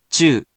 We have our computer friend, QUIZBO™, here to read each of the hiragana aloud to you.
#1.) Which hiragana youon do you hear? Hint: 【chu】
In romaji, 「ちゅ」 is transliterated as 「chu」which sounds sort of like the saying the English word「chew」.